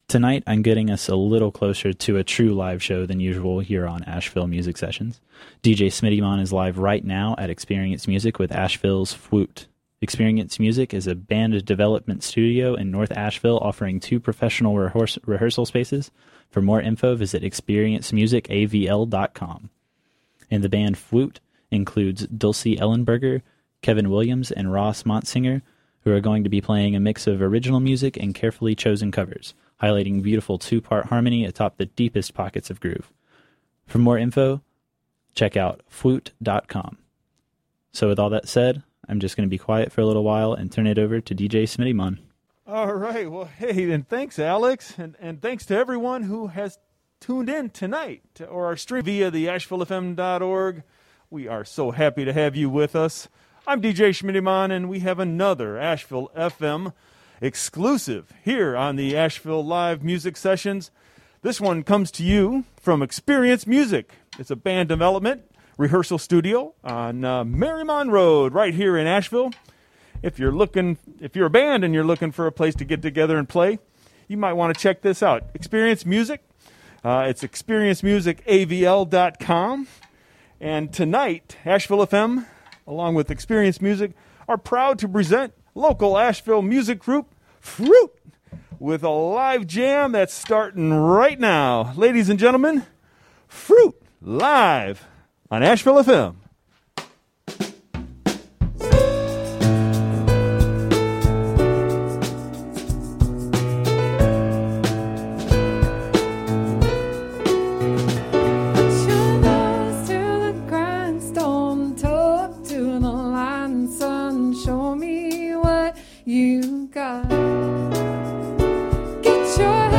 Explicit language warning